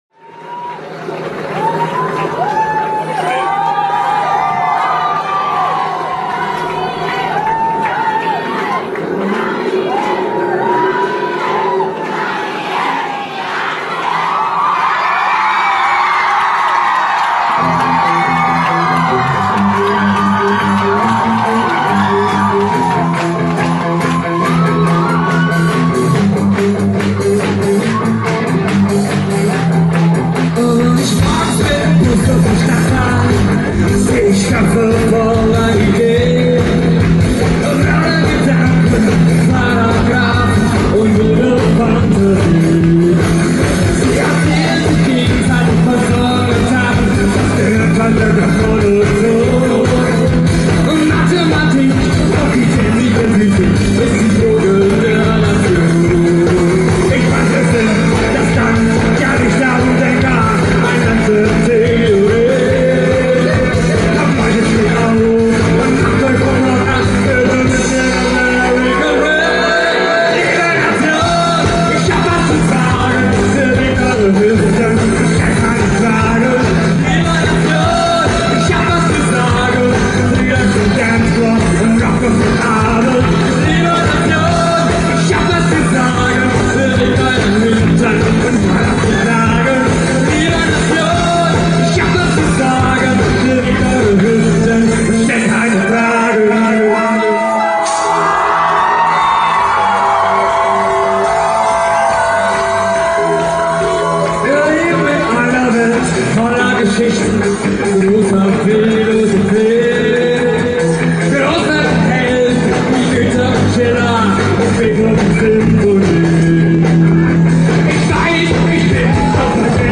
Das Auftaktkonzert findet in München im Backstage089 statt.
Auch die Akkustik lässt stellenweise zu wünschen übrig.